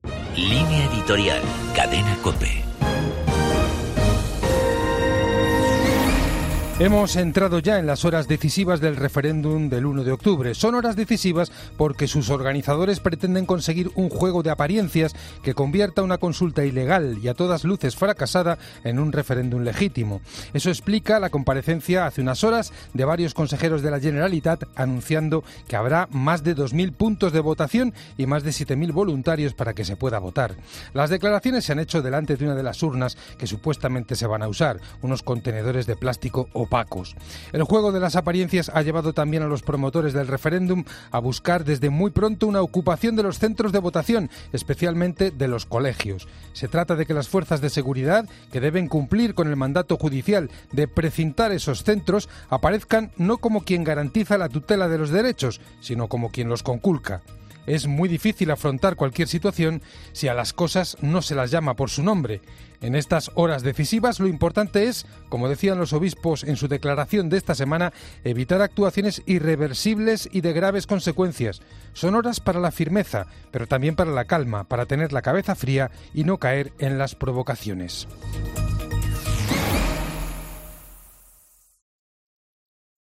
Línea Editorial de la Cadena COPE